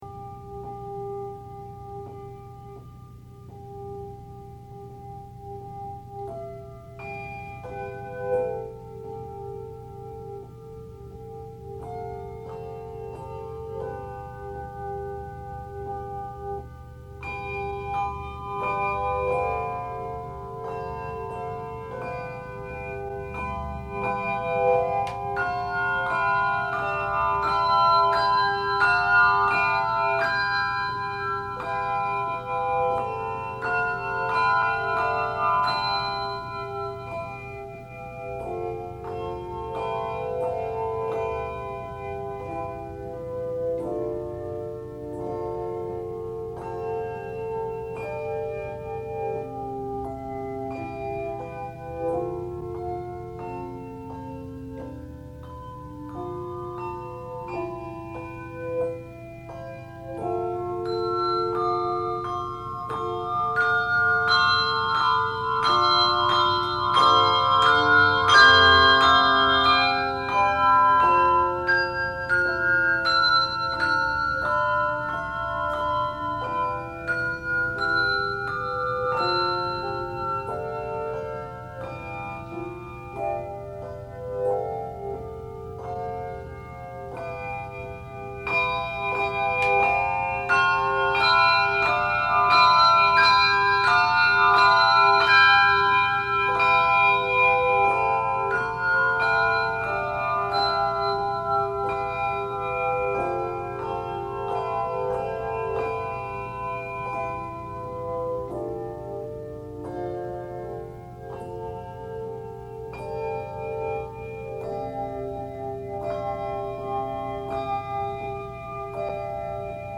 is played with a mallet.
builds to a grand climax ending with full chords.